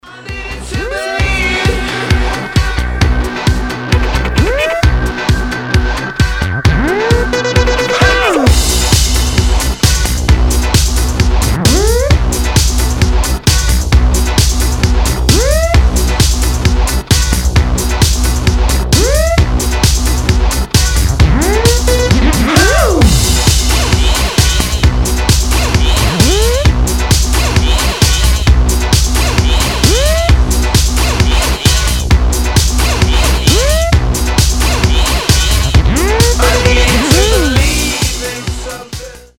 • Качество: 320, Stereo
ритмичные
Electronic
acid house